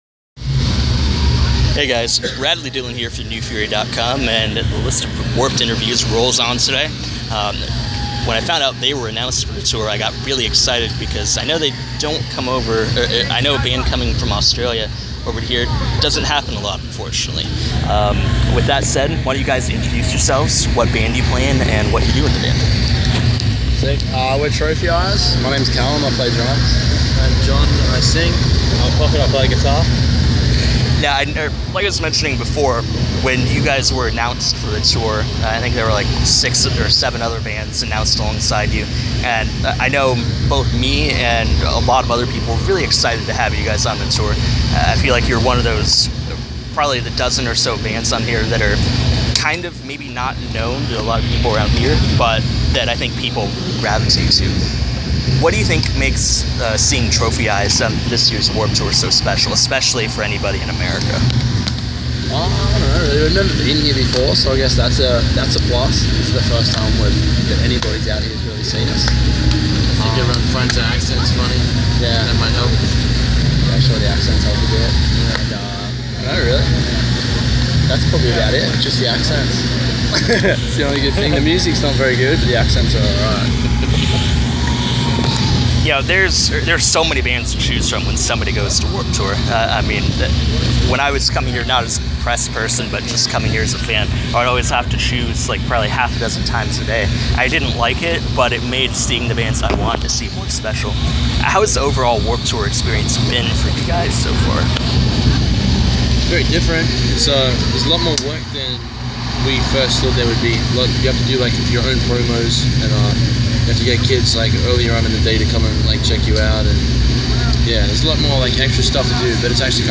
Interview: Trophy Eyes
I caught up with the guys from Trophy Eyes at Warped Tour, where we discussed their maiden voyage to the USA, the Warped experience, and their new album.